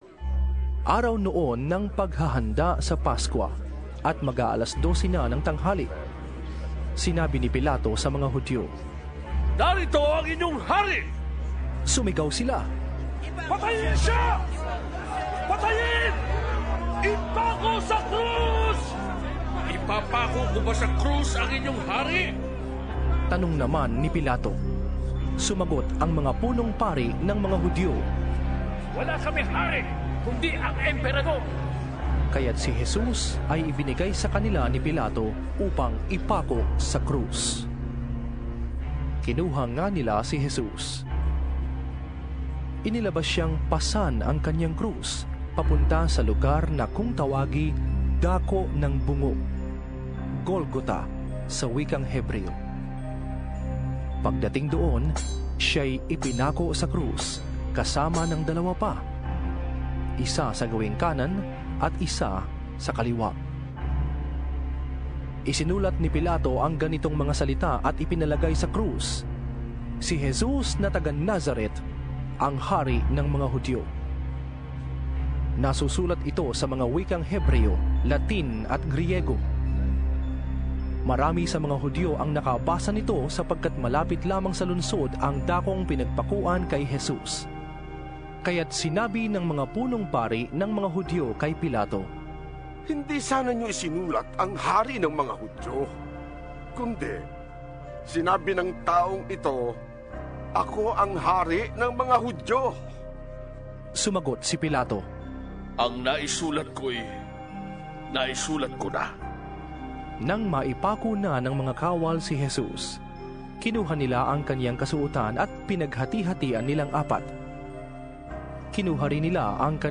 Here is the second part of a dramatisation of the last hours in the life of our Saviour, as presented by the Philippine Bible Society.